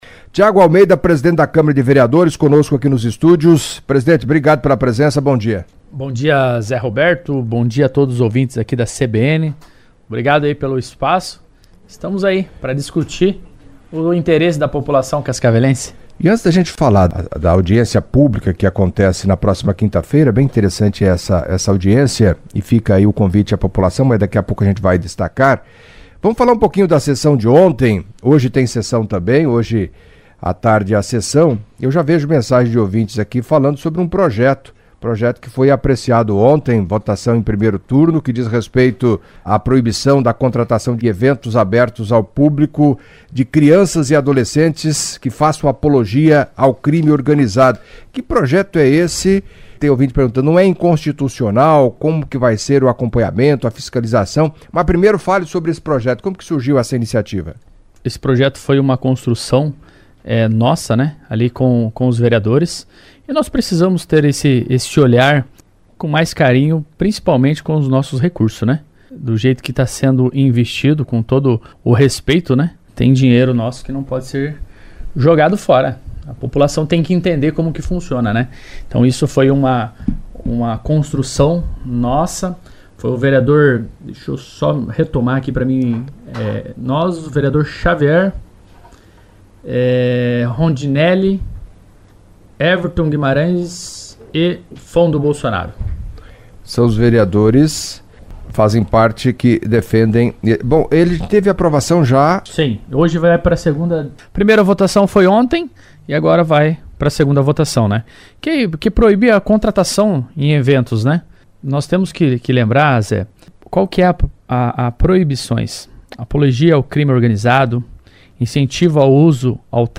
Em entrevista à CBN Cascavel nesta terça-feira (25), Tiago Almeida, presidente da Câmara de Vereadores, ao responder dúvidas de ouvitnes destacou o trabalho realizado até o momento; gestão dos recursos e pregou seriedade e transparência na aplicação dos recursos públicos.